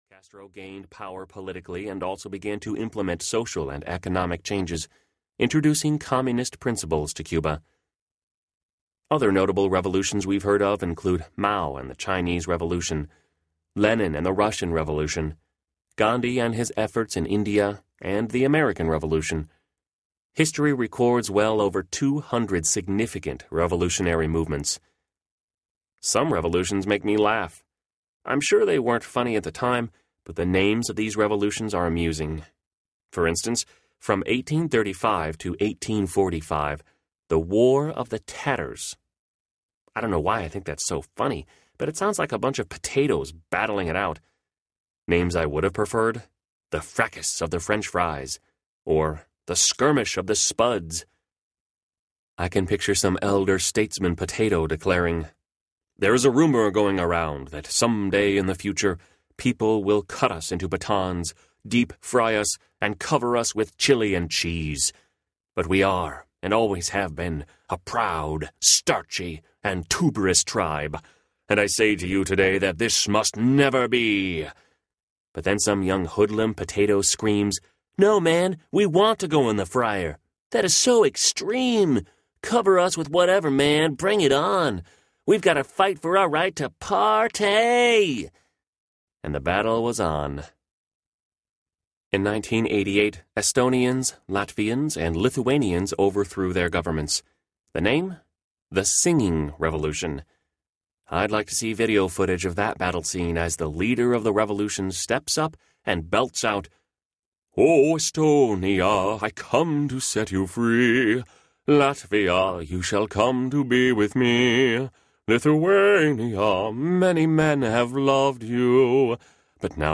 Guerrilla Lovers Audiobook